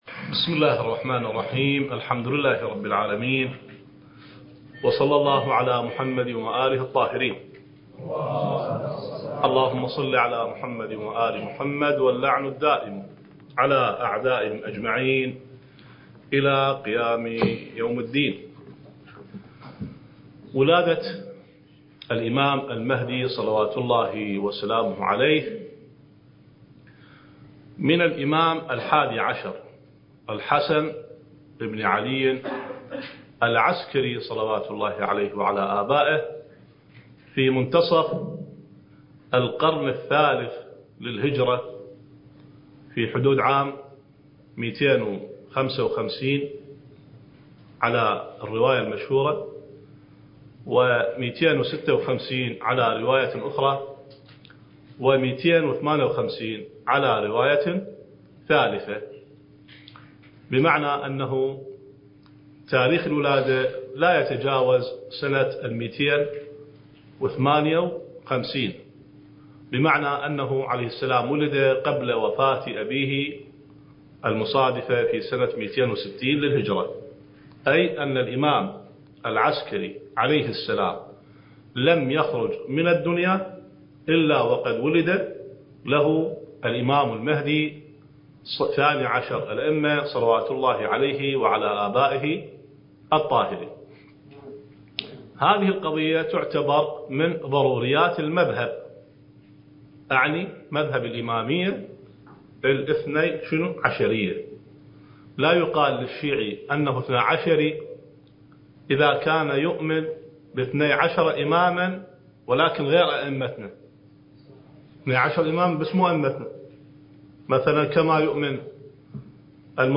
الدورة المهدوية الأولى المكثفة (المحاضرة الثالثة عشر)
المكان: النجف الأشرف